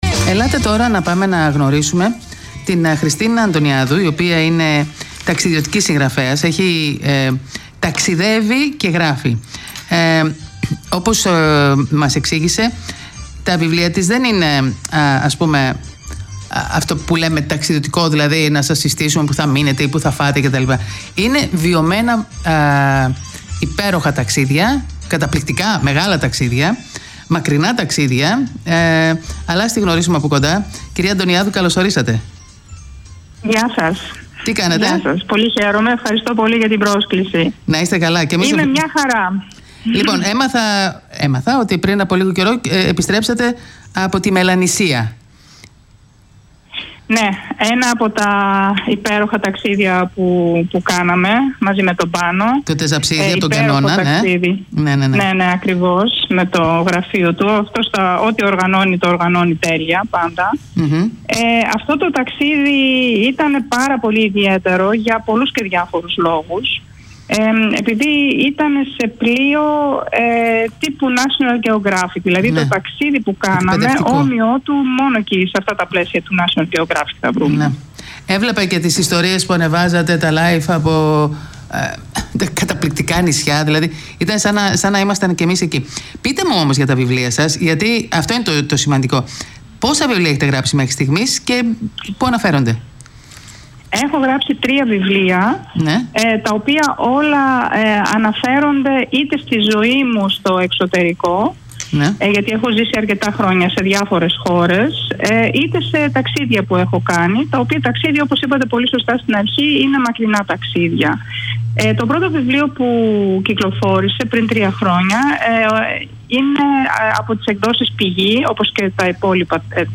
Συνεντευξη